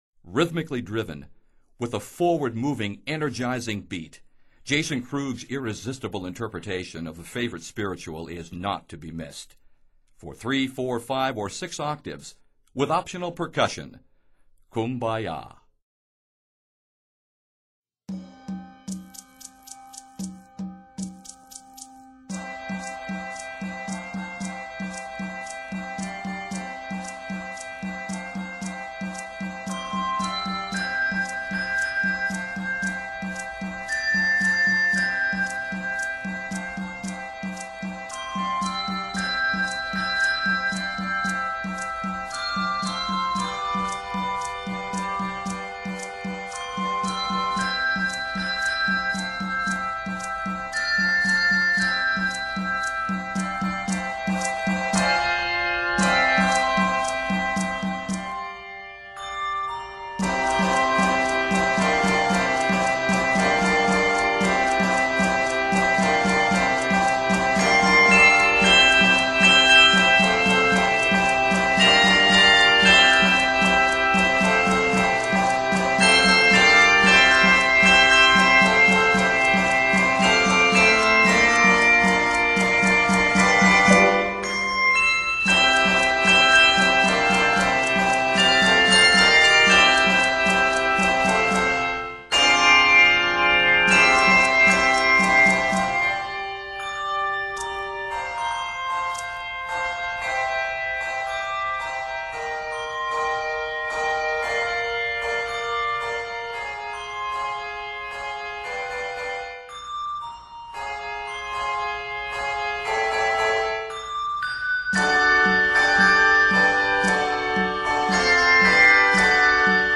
It is scored in C Major and D Major.